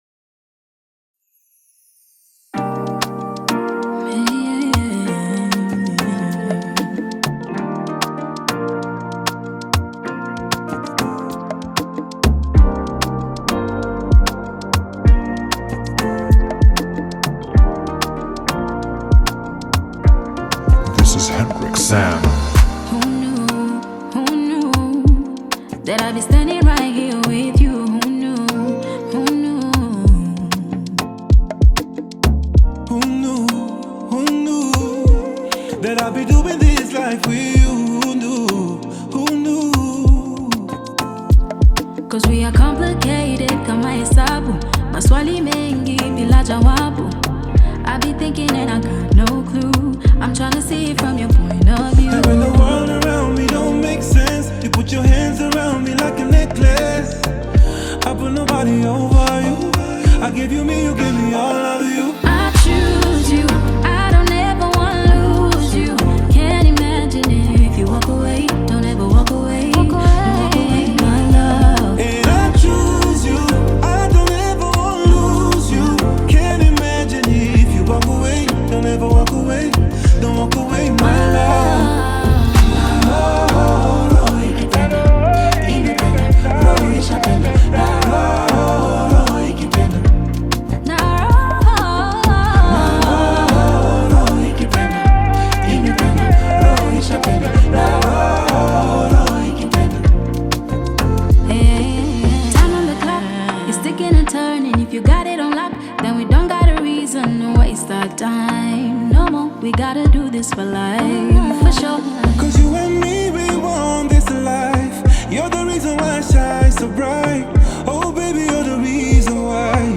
smooth vocals
blends Afrobeat with contemporary sounds
With its high energy tempo and catchy sounds